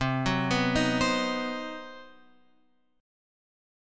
C Minor Major 7th Flat 5th